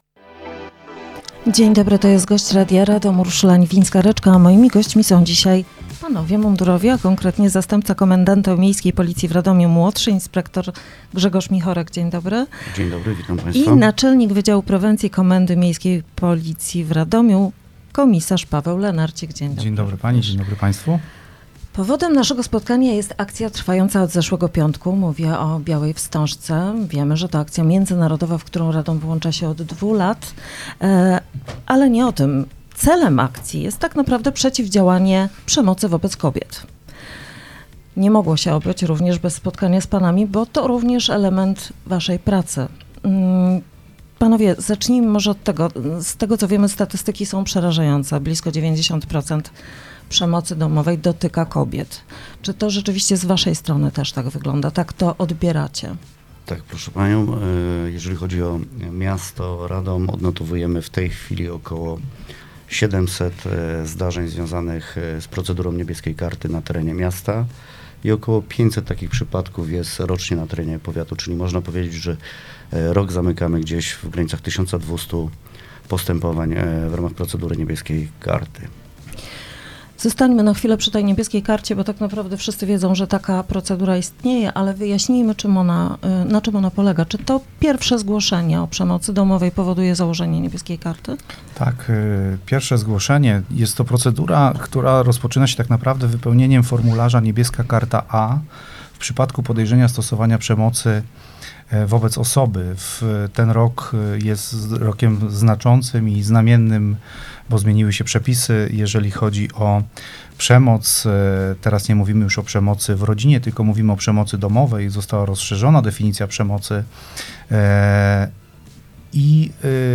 w studiu Radia Radom. Tematem rozmowy była akcja „Biała Wstążka”.
Rozmowa dostępna również na facebookowym profilu Radia Radom: